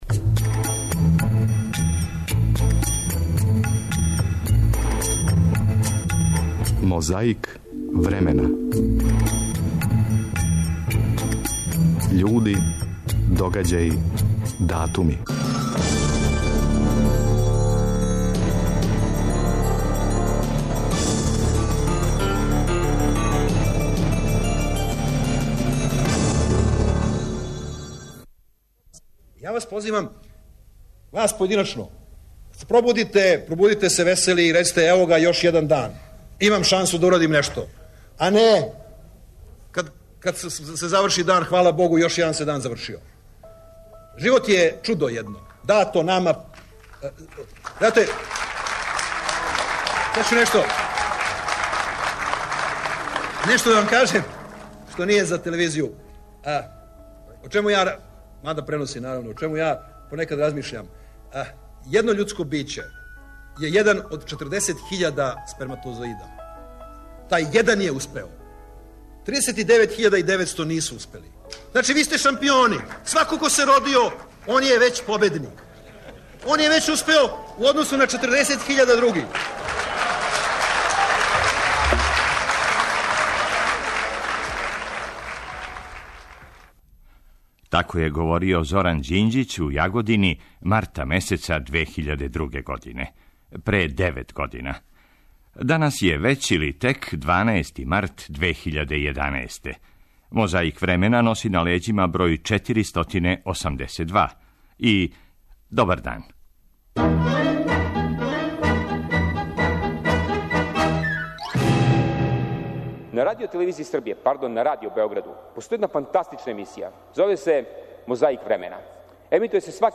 Освежавамо сећање или оно нас. Прво слушамо шта је Зоран Ђинђић говорио у Јагодини, марта 2002. године.